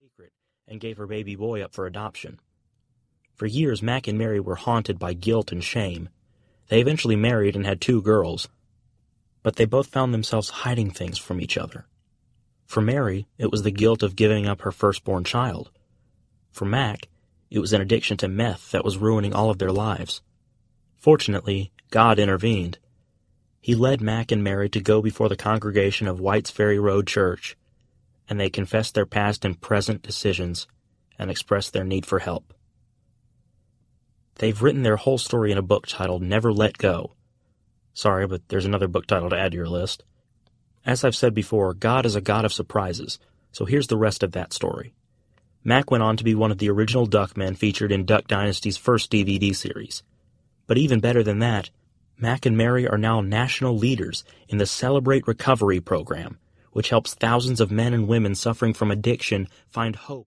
Young and Beardless Audiobook
5.57 Hrs. – Unabridged